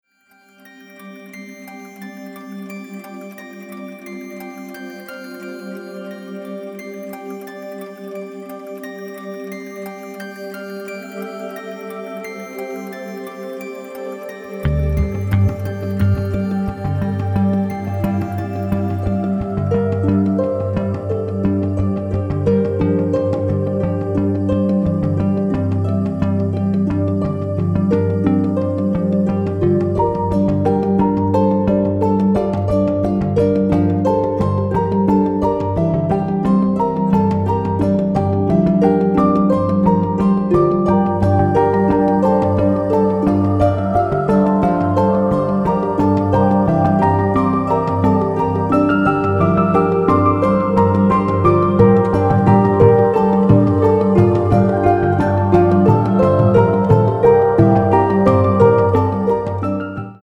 orchestra and chorus